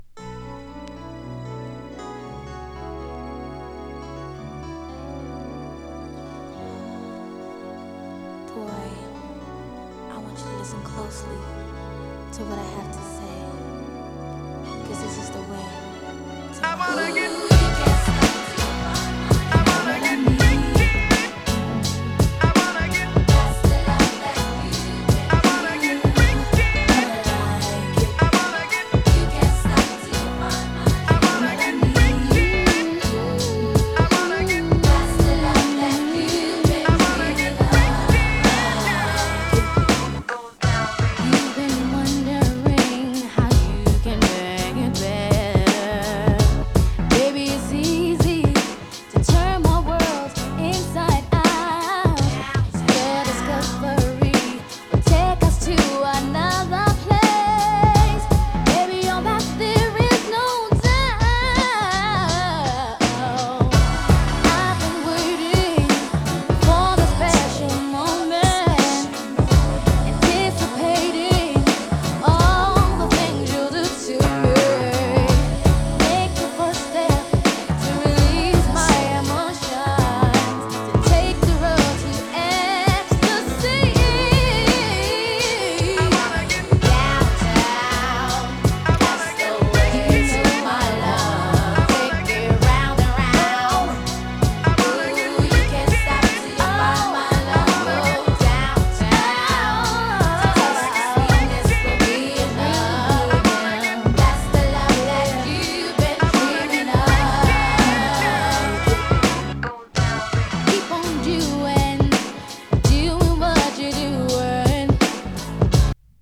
90s RnB
90sに活躍したガールズ・トリオによる初期のヒット曲。